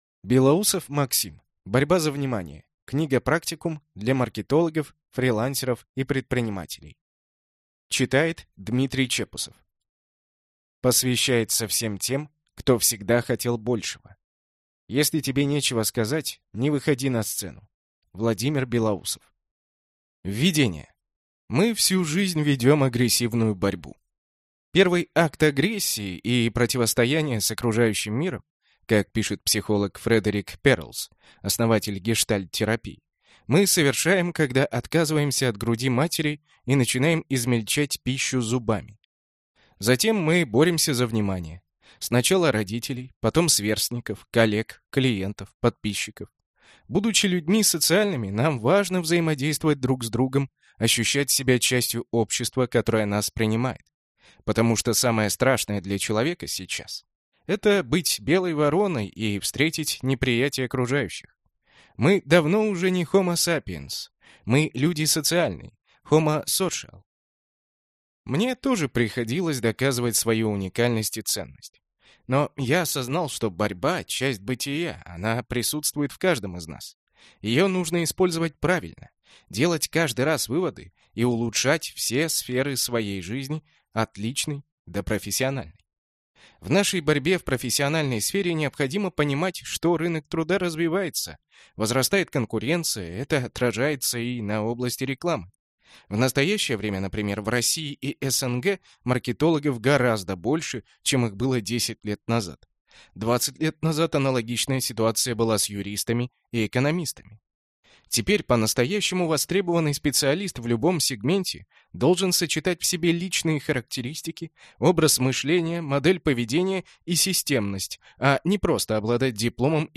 Аудиокнига Борьба за внимание. Книга-практикум для маркетологов, фрилансеров и предпринимателей | Библиотека аудиокниг